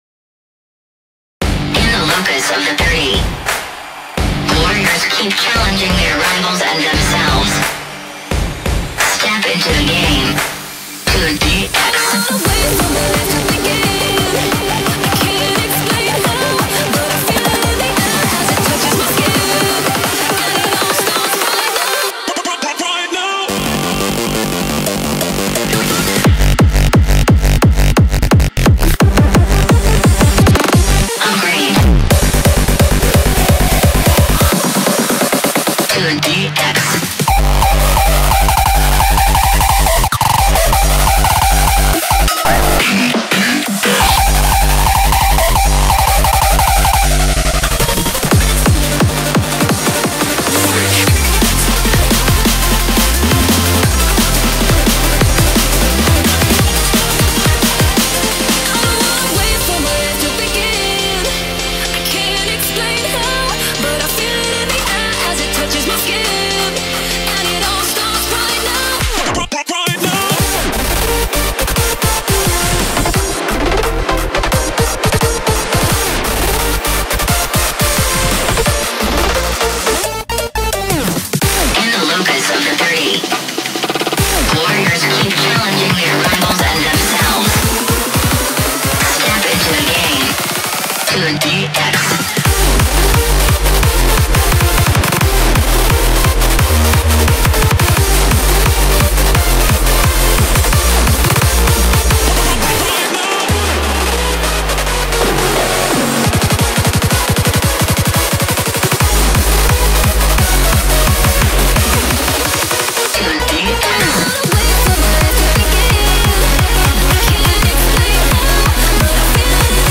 BPM87-174
Audio QualityPerfect (Low Quality)